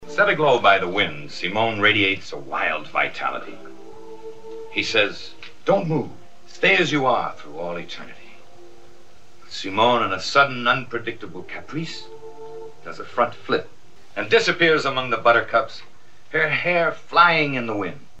Hörprobe mit Lex' eigener Stimme Hörprobe mit Lex' eigener Stimme